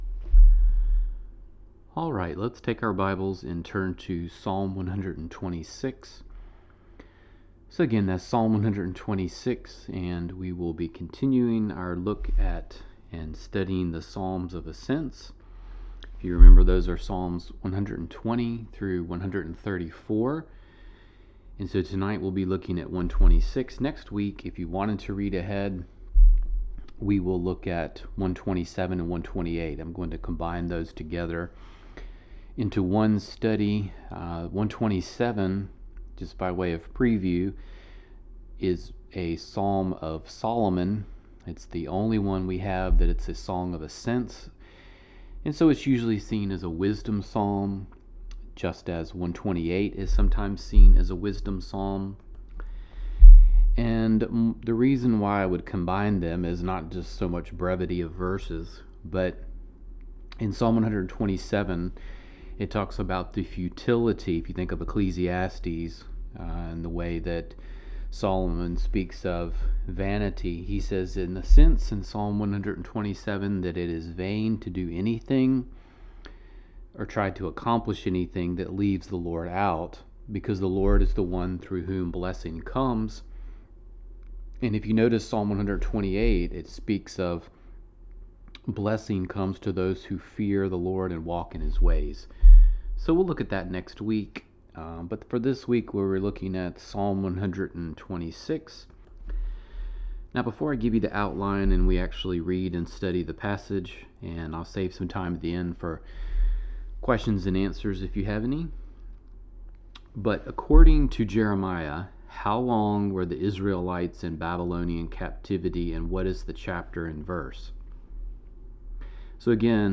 Sermons | Decatur Bible Church